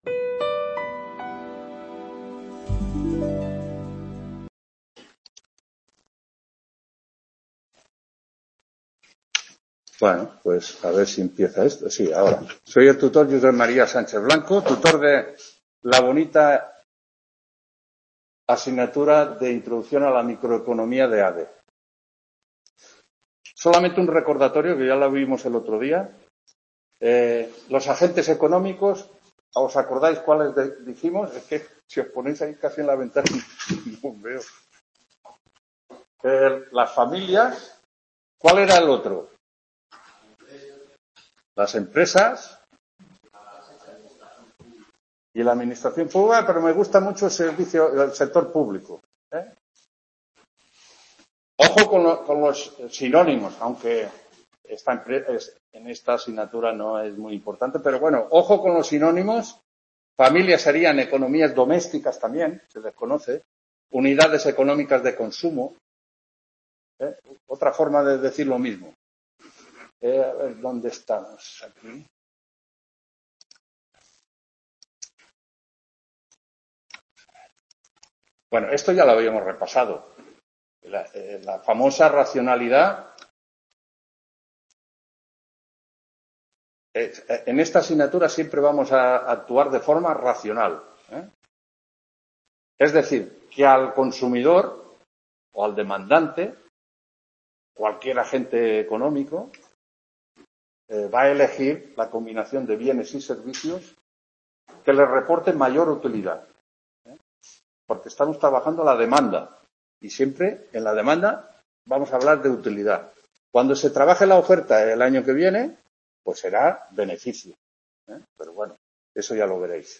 3ª TUTORÍA INTRODUCCIÓN A LA MICROECONOMÍA (ADE Nº 233… | Repositorio Digital